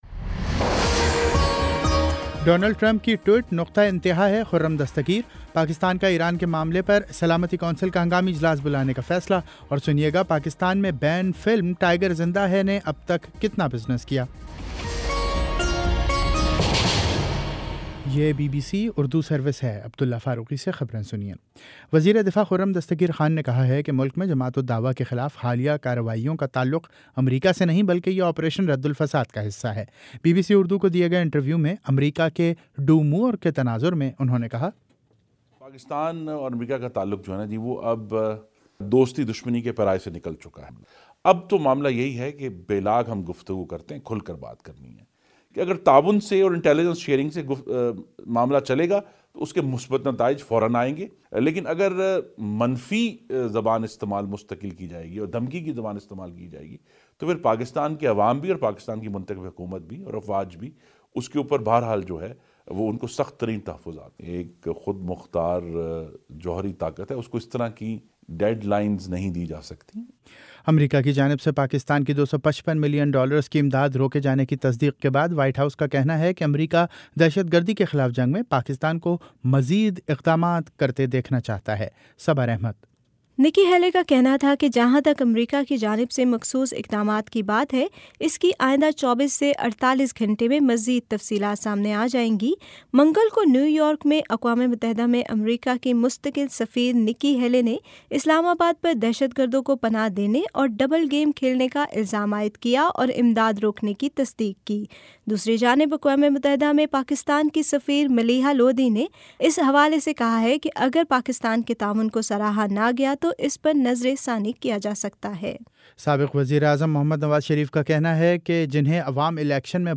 جنوری 03 : شام چھ بجے کا نیوز بُلیٹن